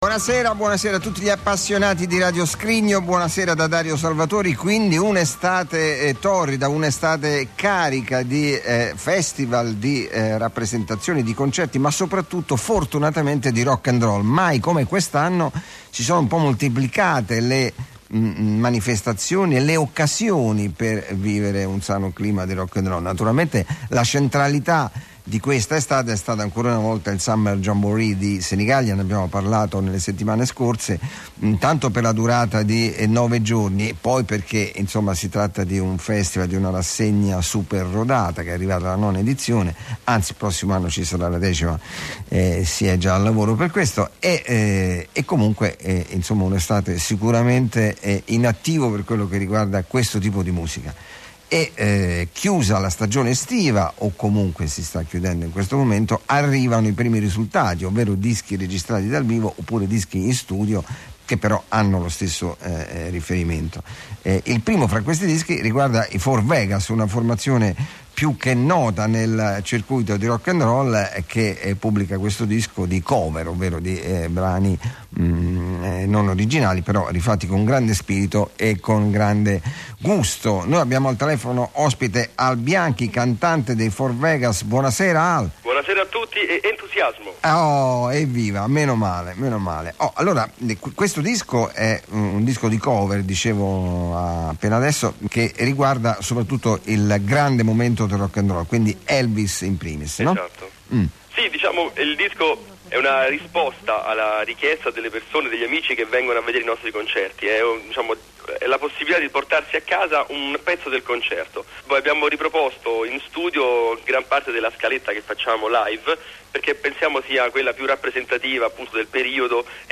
intervista4v.mp3